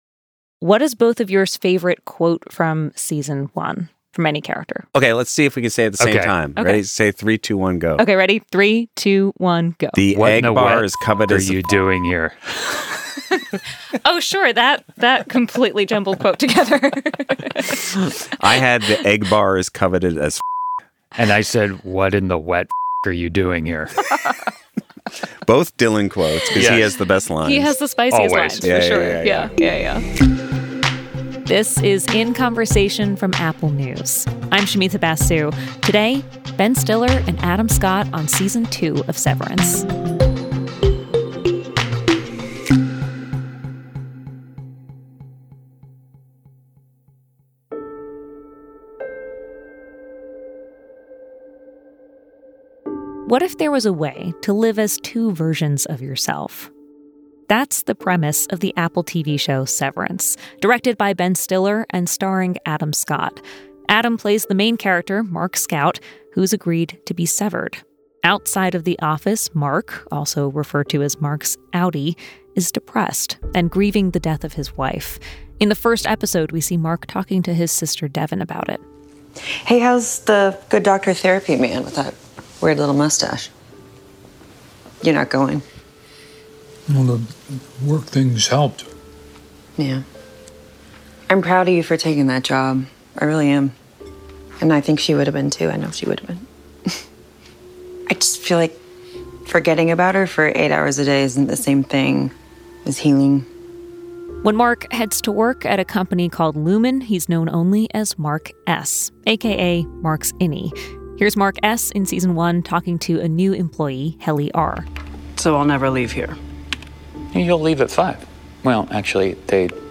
… continue reading 173 Episoden # News # Apple News # News Talk # New Podcast Series